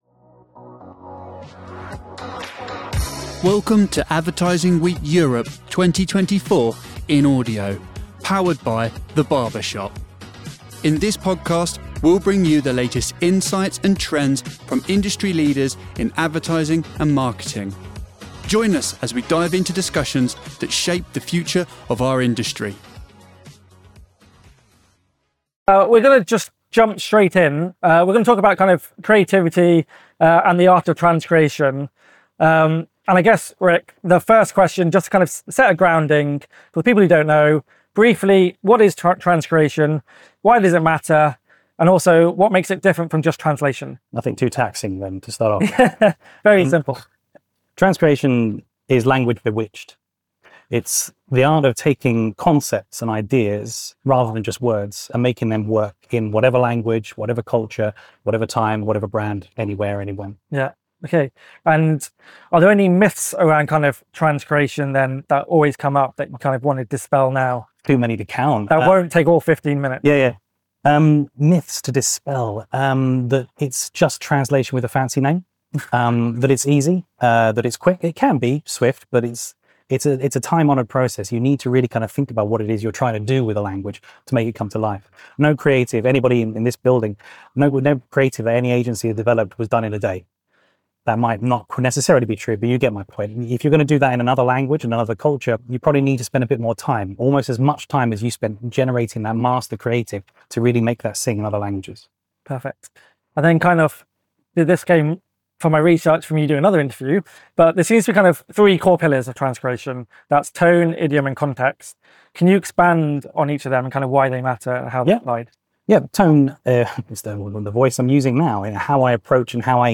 awe24-wed-id66-tag-interview.mp3